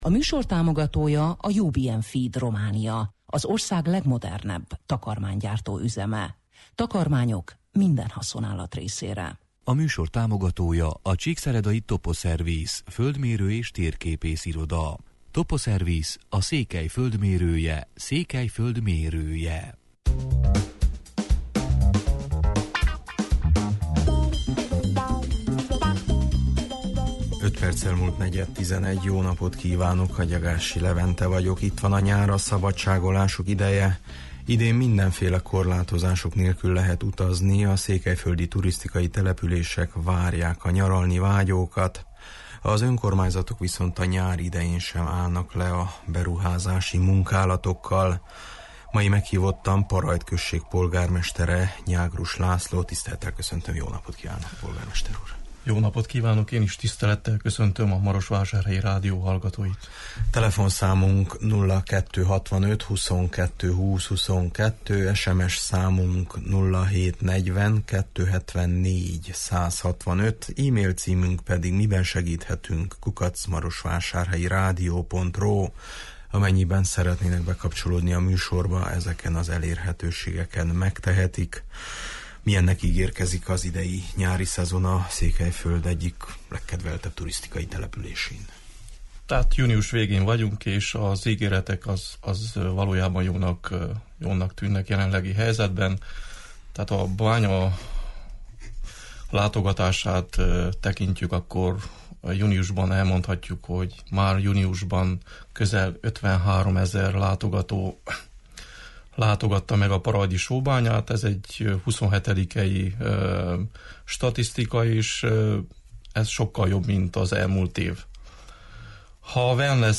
Parajd község polgármesterével, Nyágrus Lászlóval beszélgetünk: